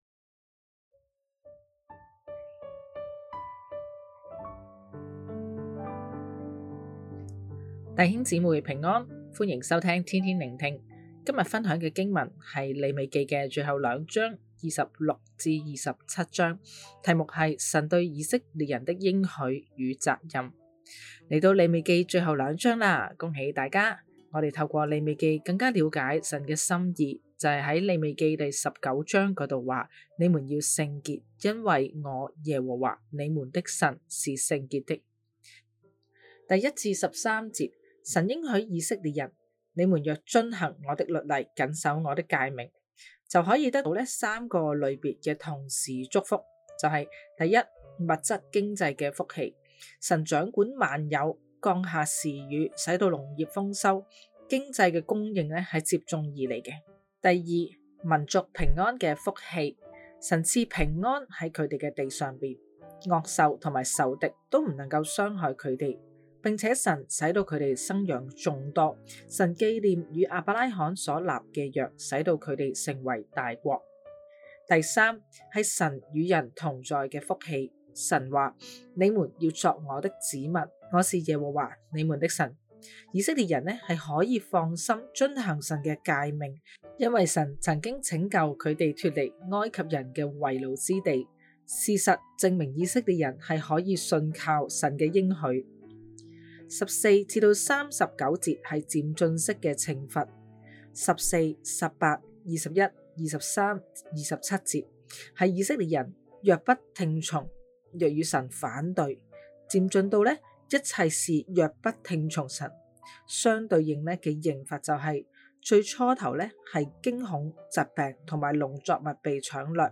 普通话录音连结🔈